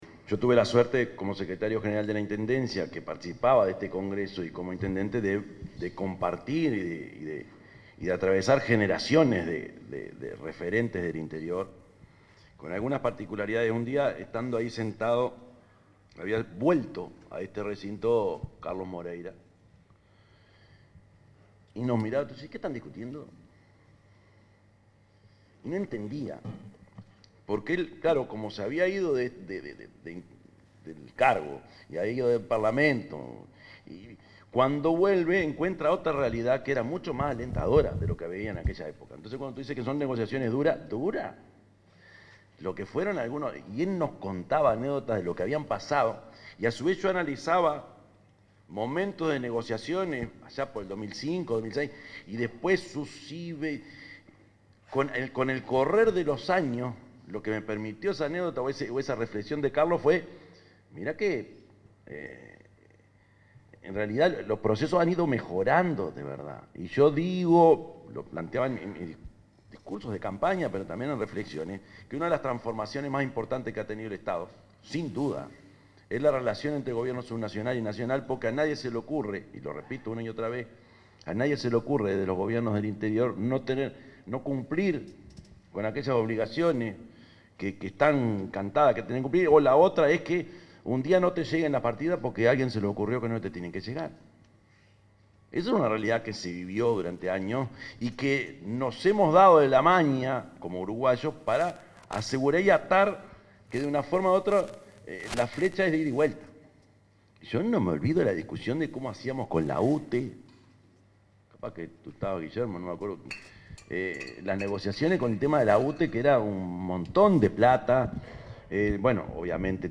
El presidente de la República, Yamandú Orsi, expuso en la segunda sesión plenaria del Congreso de Intendentes.